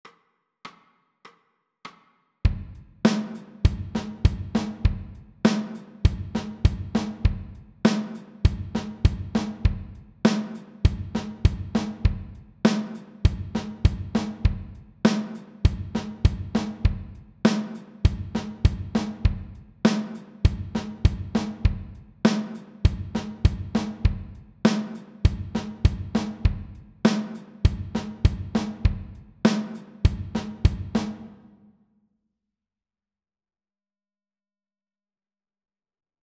TRANSCRIBING DRUM GROOVES
You will hear a 4 beat intro followed by a one bar drum groove repeated 12 times.